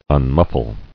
[un·muf·fle]